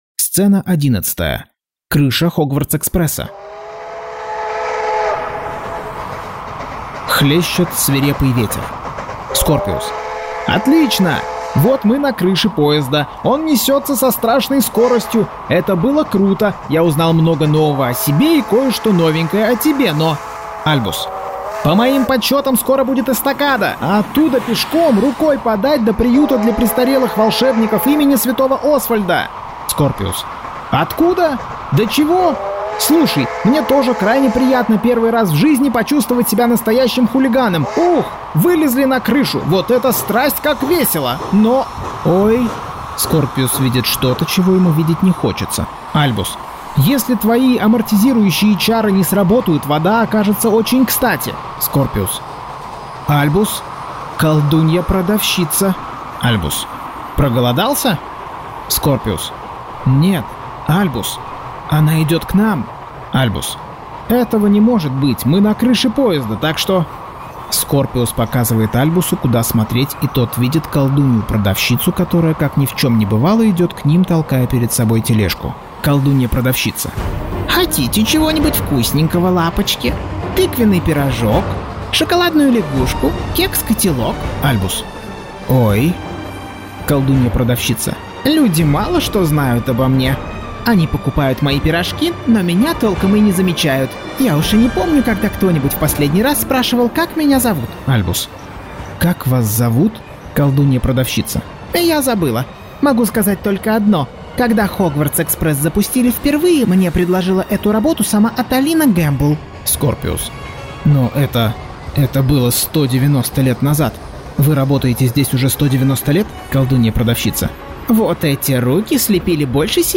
Аудиокнига Гарри Поттер и проклятое дитя. Часть 8.